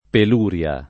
pel2rLa] s. f. — lett. il sing. pelurie [pel2rLe]: la pelurie delle braccia [